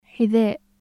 無声・咽頭・摩擦音/ħ/
حذاء /ħiðaːʔ/